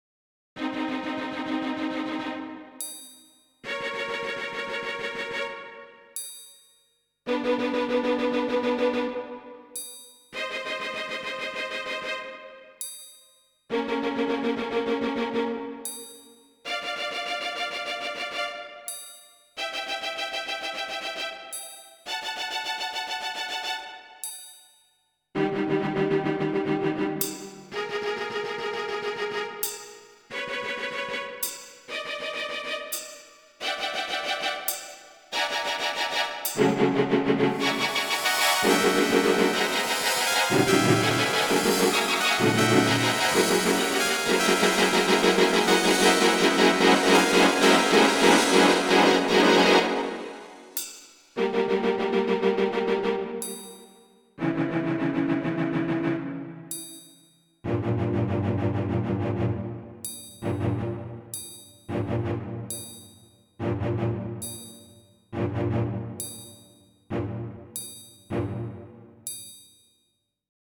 Запись 1995 г. Инструменты Ensoniq и Kurzweil.